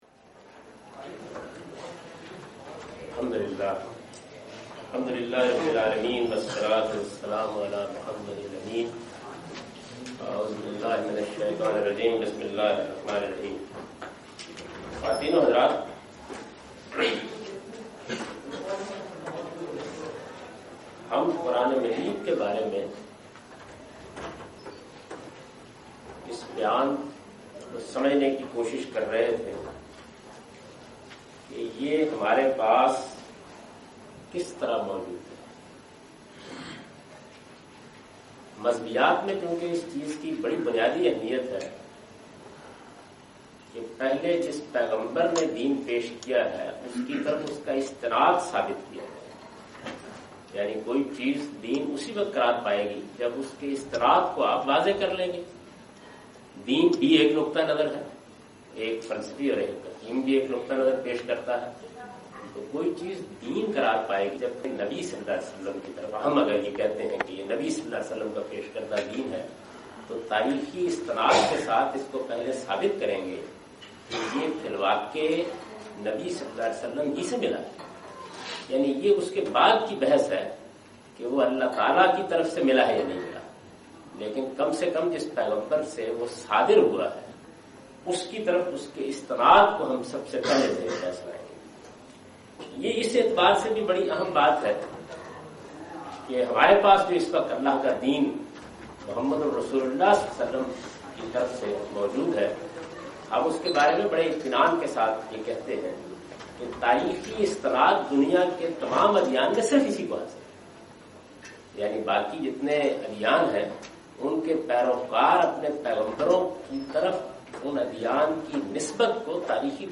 A comprehensive course on Islam, wherein Javed Ahmad Ghamidi teaches his book ‘Meezan’.
In this lecture series, he not only presents his interpretation of these sources, but compares and contrasts his opinions with other major schools developed over the past 1400 years. In this lecture he teaches basic principles on which he laid foundation of his whole work.